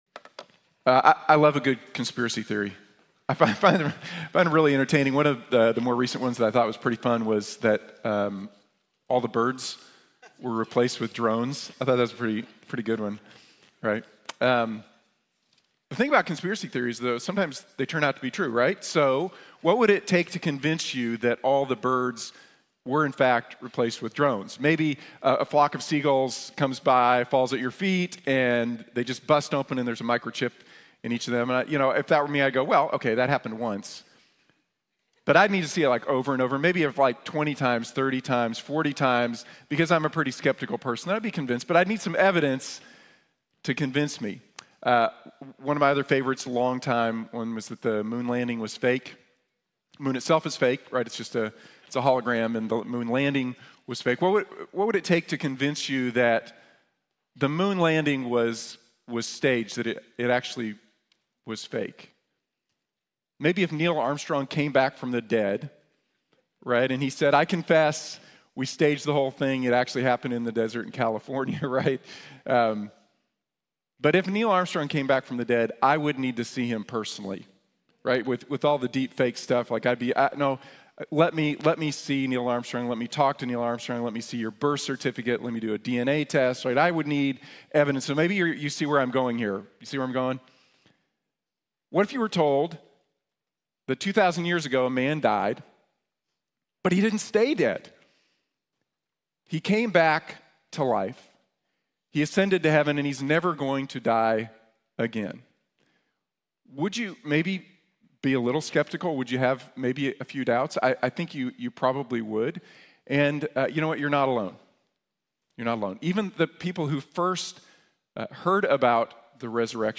From Doubt to Devotion | Sermon | Grace Bible Church